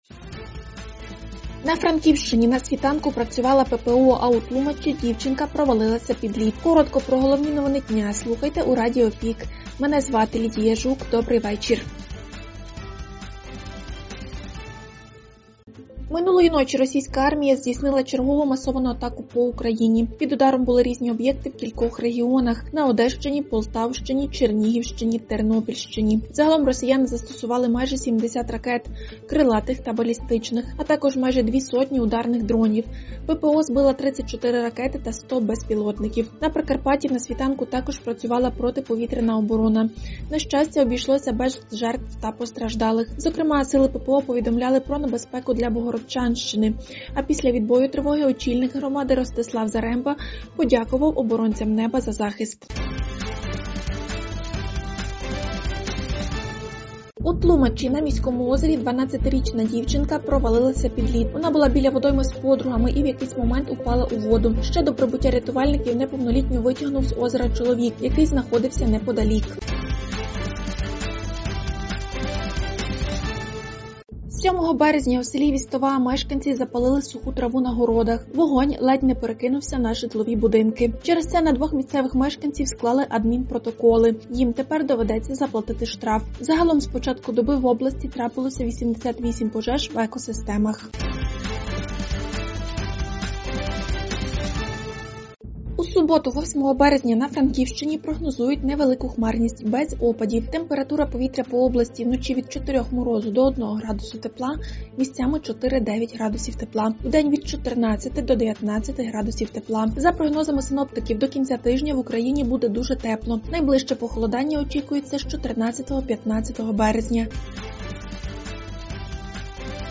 Радіо ПІК: головні новини України та Прикарпаття за сьоме березня (ПРОСЛУХАТИ)
Пропонуємо Вам актуальне за день у радіоформаті.